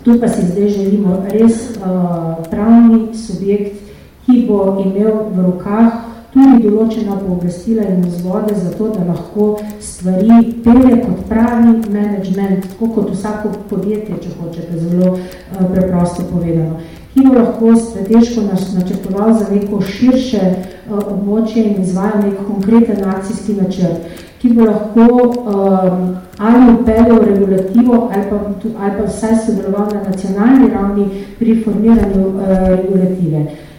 Generalna direktorica direktorata za turizem na ministrstvu za gospodarstvo, šport in turizem Dubravka Kalin o tem, kaj si še želijo